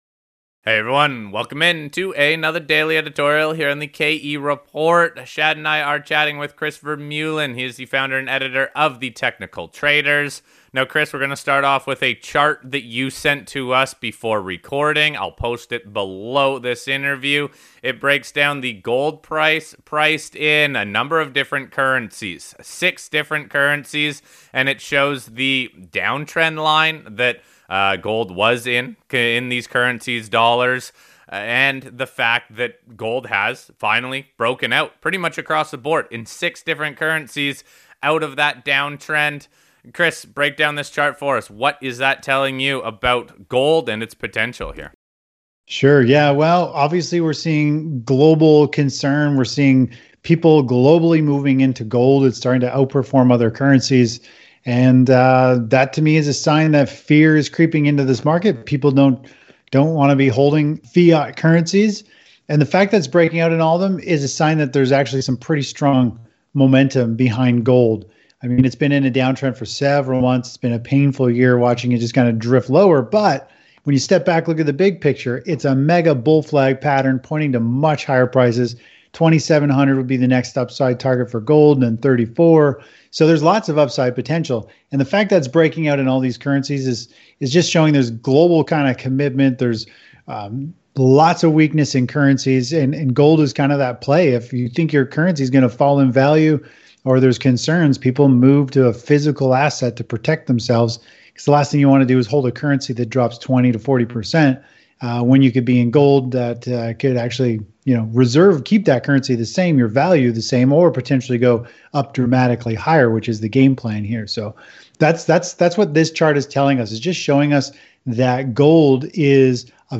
This leads into a discussion on the potential of a broad shift of investor interest toward defensive or safer assets.